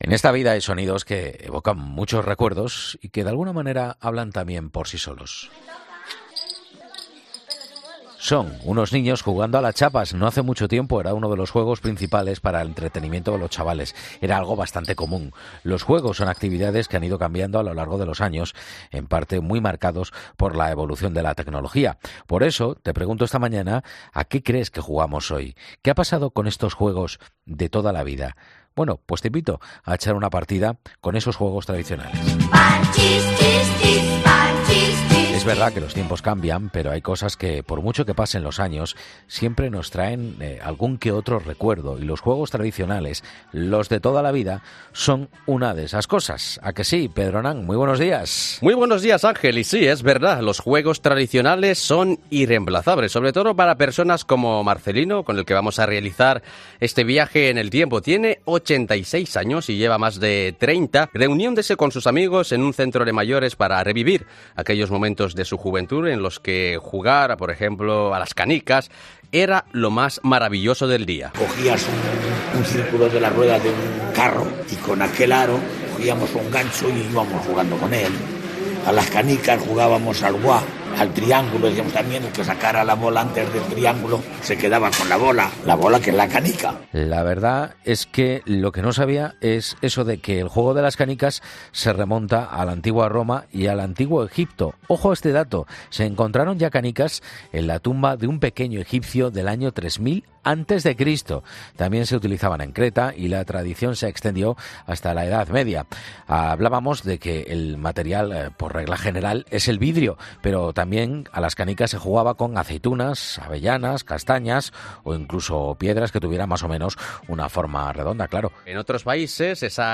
entrevistamos desde el centro de mayores Luca de tena de Madrid